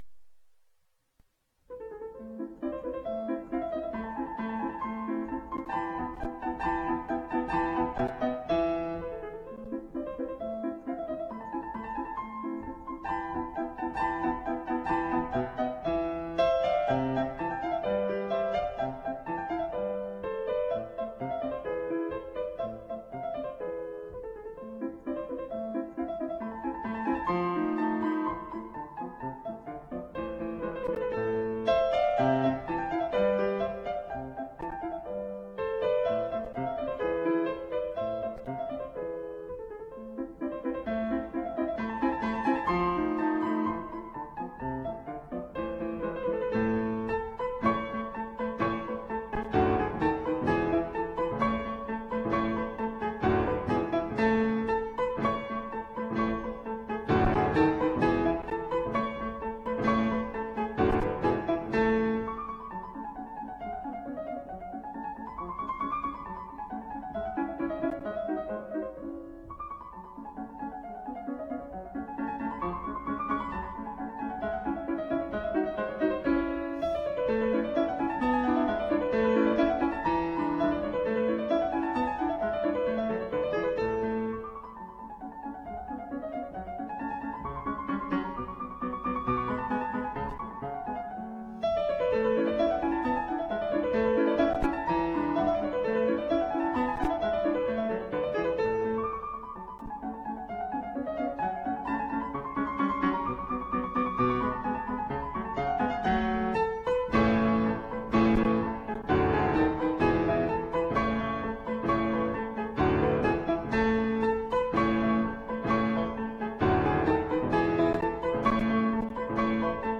Baby Grand Piano - a click will make it play